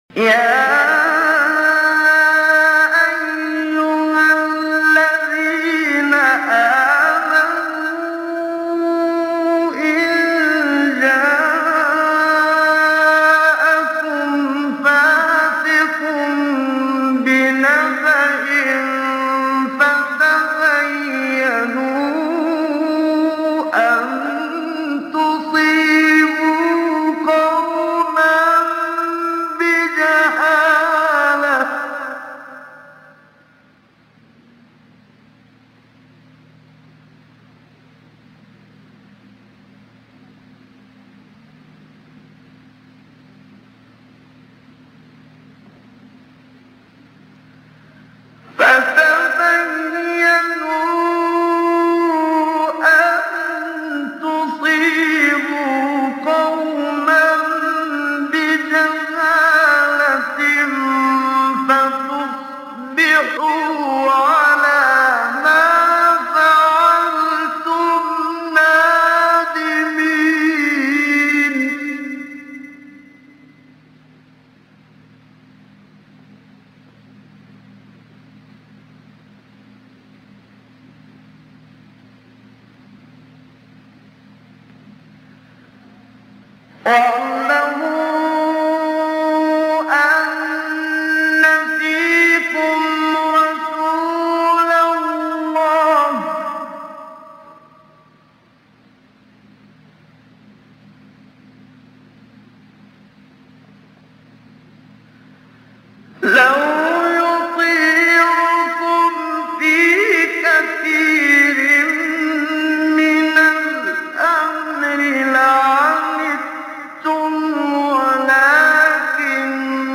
آیه 6-7 سوره حجرات محمد صدیق منشاوی | نغمات قرآن | دانلود تلاوت قرآن